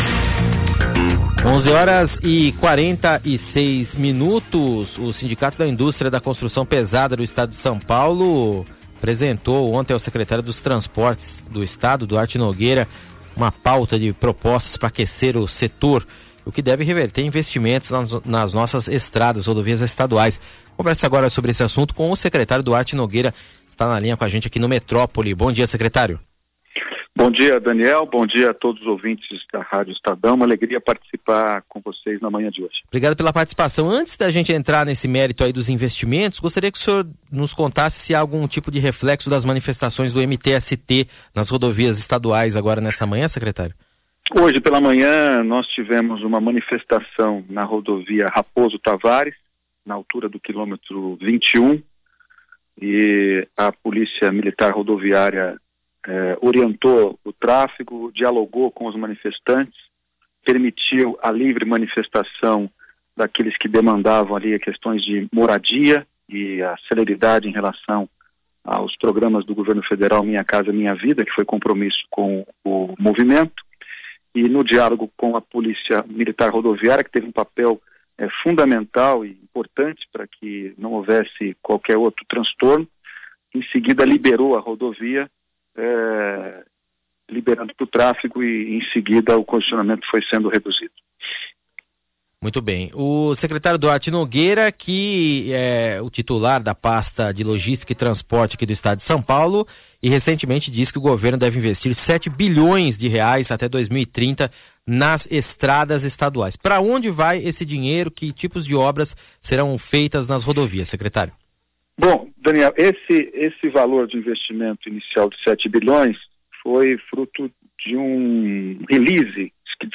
Entrevista do secretário Duarte Nogueira à rádio Estadão